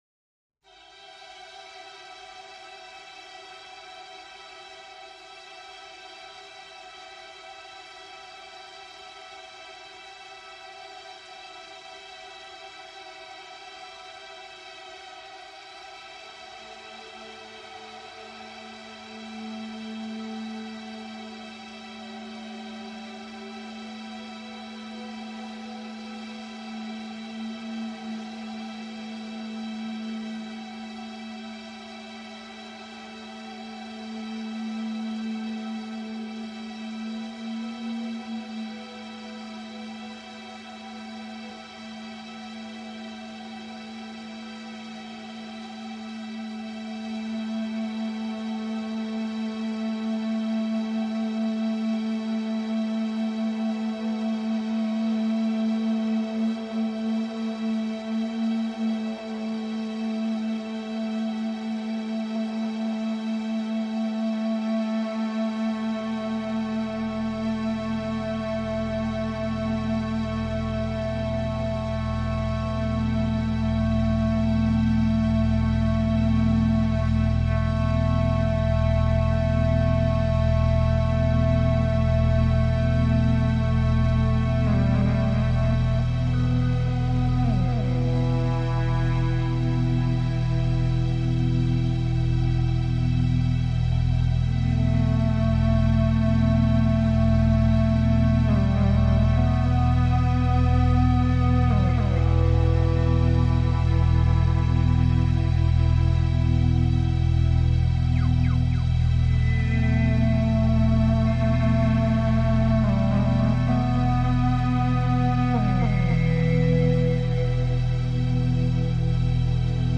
Dazu gibt's jede Menge Rock-Musik von bekannten und weniger bekannten Bands.